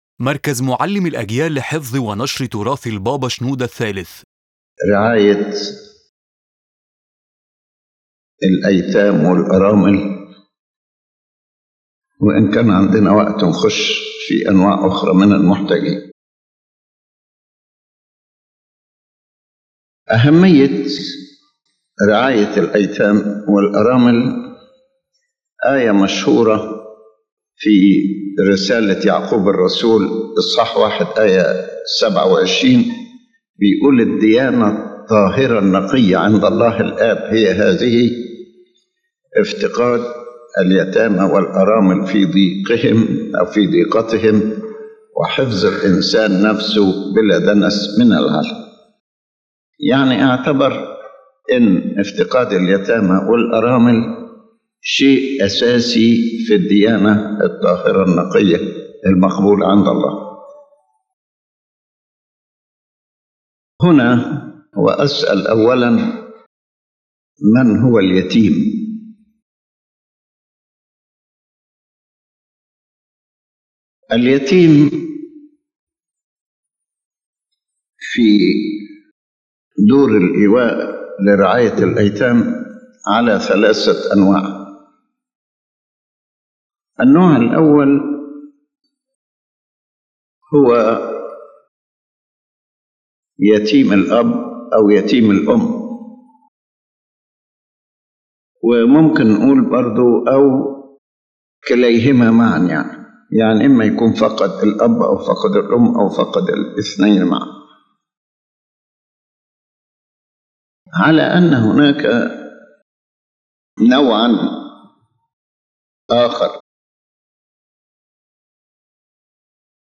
Pope Shenouda III speaks about caring for orphans, explaining their types, needs, and the common mistakes in dealing with them. He places strong emphasis on the psychological and spiritual support required to provide a loving family-like environment that compensates for what they have lost.